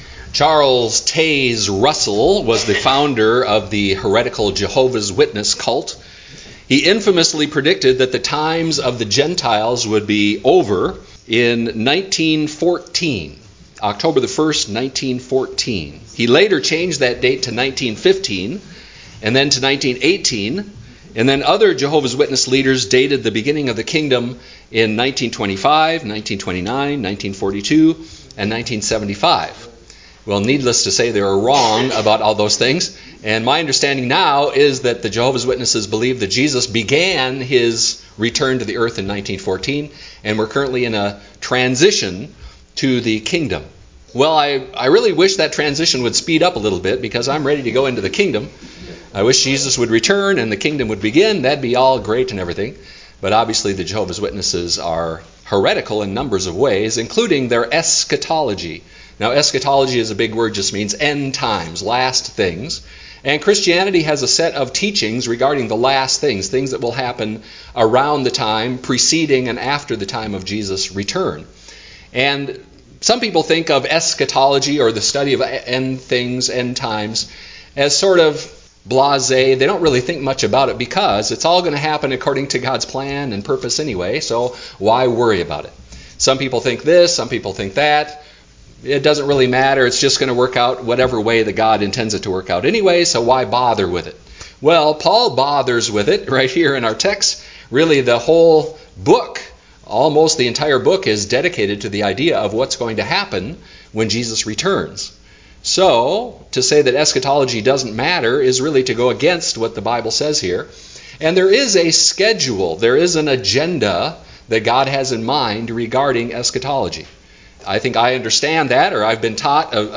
Service Type: Special service